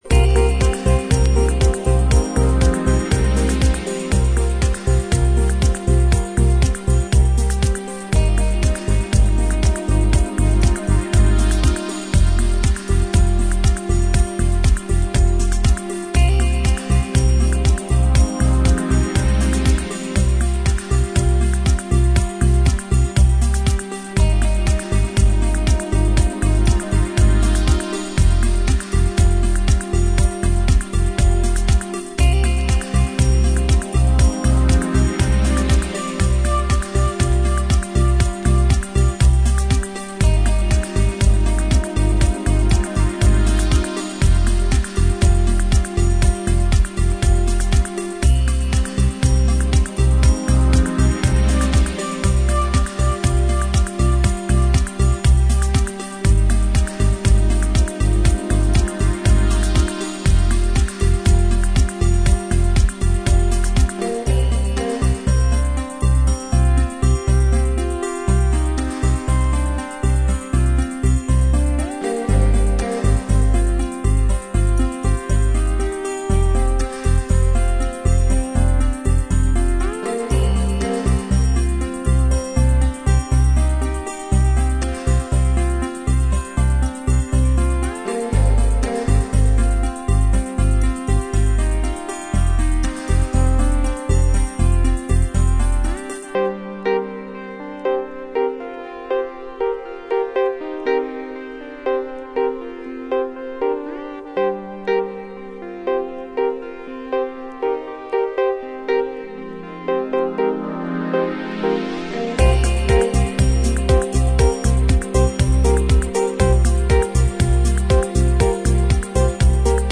acoustic and melancholic piece